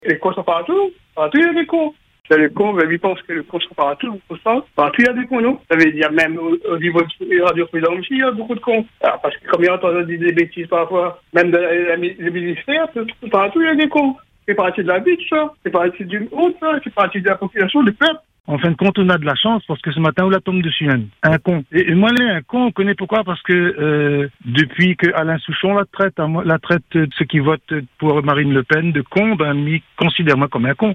Vos témoignages affluent, parfois drôles, parfois grinçants, souvent très parlants.